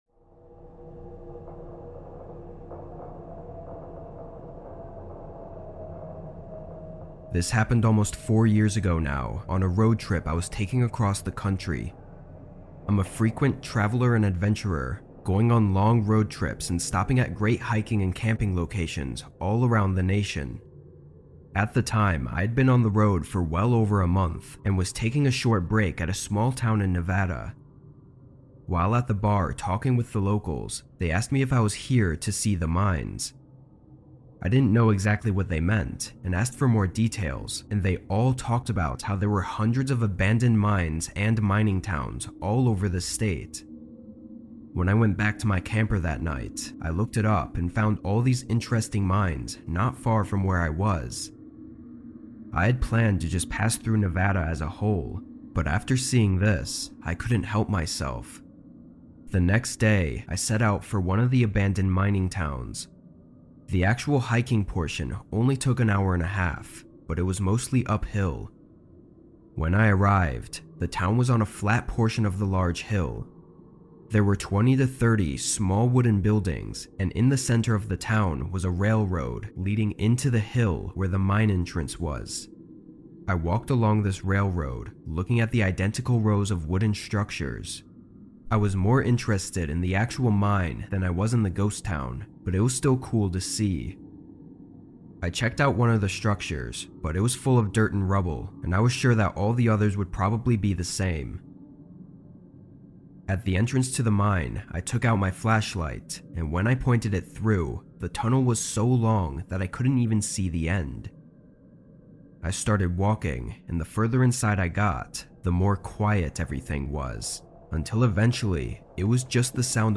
A True Horror Story Told in the Rain | Vol. 1